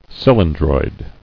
[cyl·in·droid]